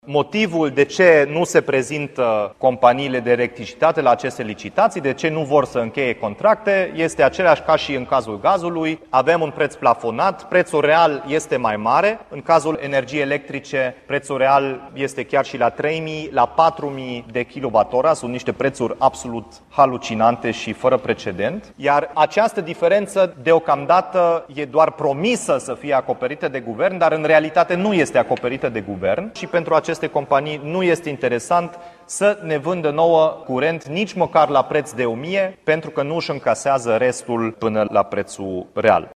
La Timişoara, Dominic Fritz a anunţat că instituţile publice, ca Primăria sau spitalele, nu mai reuşesc să încheie noi contracte cu furnizorii de energie: